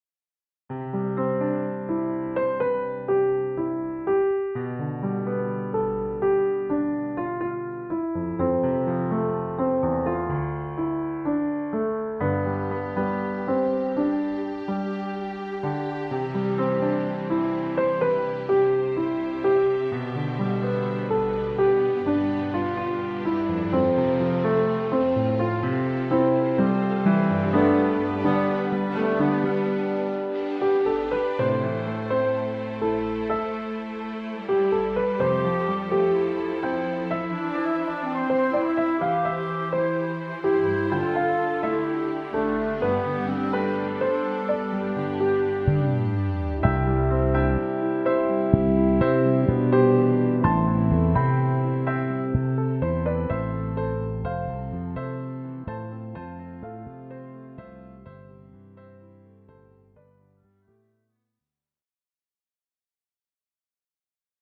2024: Quarter Finalist: Instrumental